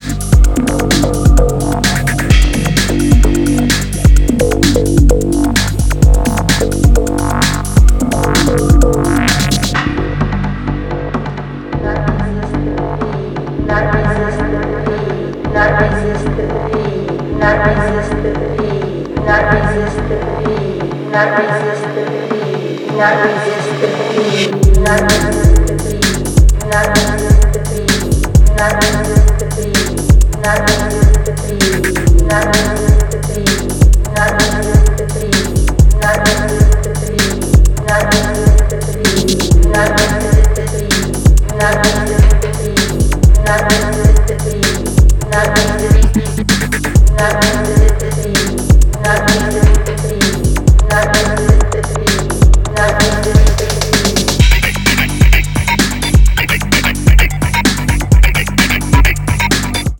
最低域から鳴るキックとメロディアスなベースライン、トランシー・シンセでフロアに暗黒の浮遊感をもたらすエレクトロ